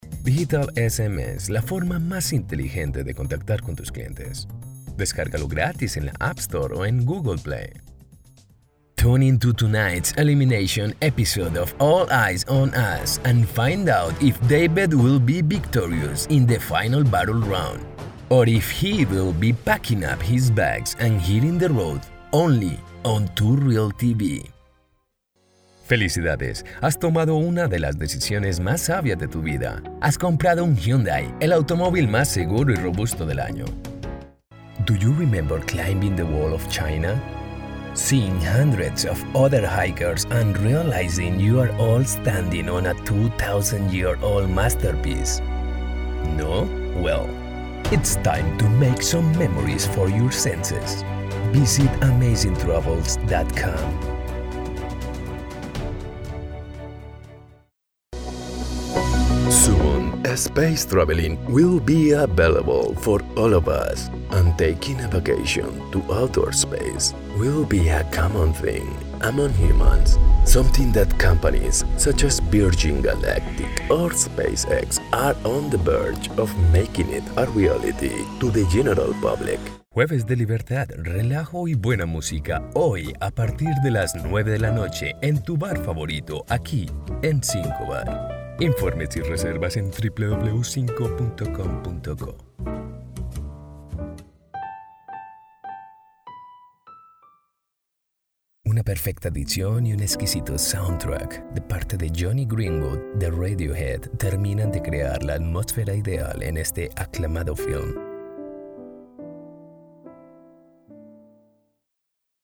I'll gladly take care of your voice-over requirements (Neutral Latin American Spanish/Neutral American English), be it for your personal or commercial use.
Sprechprobe: Werbung (Muttersprache):